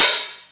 055 - HH-1O.wav